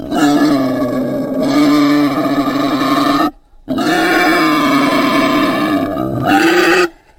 boar.ogg